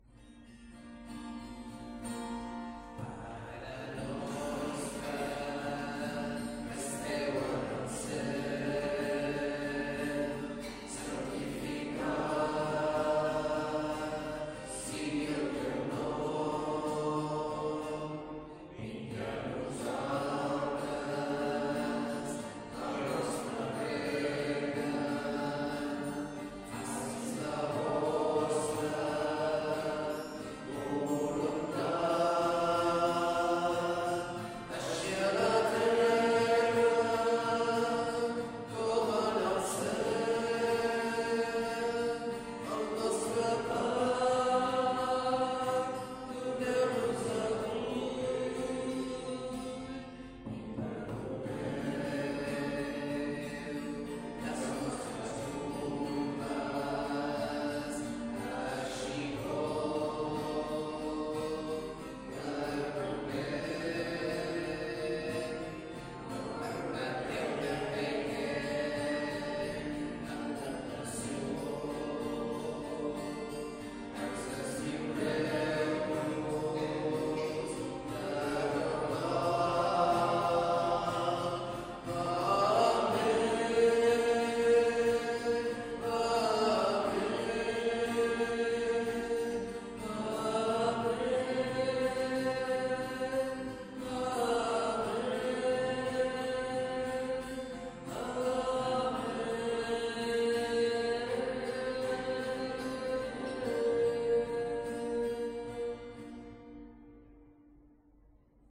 Església del Sagrat Cor - Diumenge 29 de juny de 2025
Vàrem cantar...